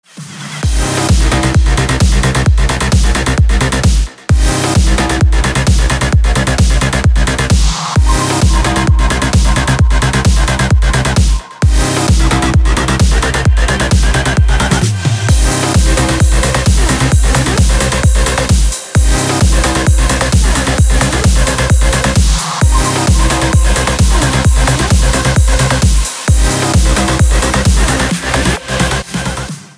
• Качество: 320, Stereo
громкие
dance
Electronic
EDM
электронная музыка
без слов
club
энергичные
progressive trance
Trance